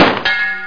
00018_Sound_CLANG2.WAV
1 channel